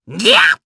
Lakrak-Vox_Attack1_jp.wav